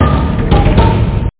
TockTaTockTock.mp3